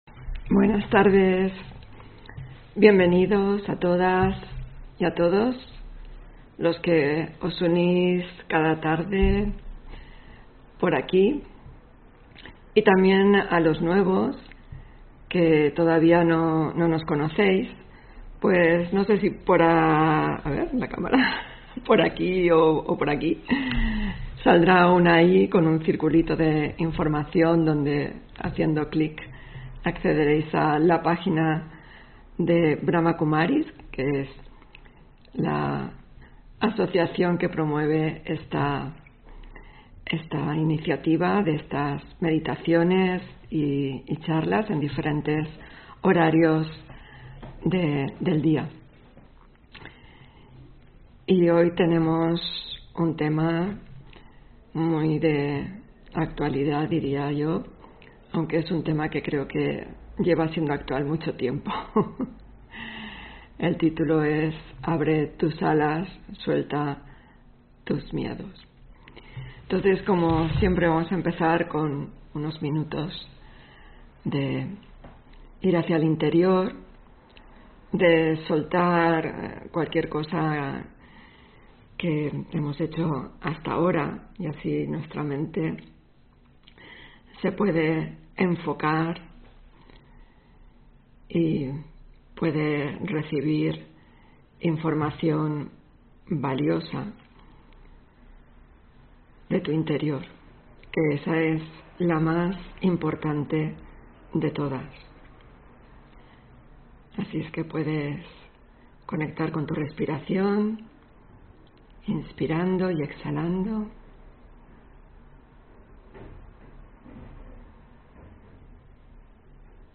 Meditación Raja Yoga: Abre tus alas y suelta tus miedos (26 Octubre 2020) On-line desde Madrid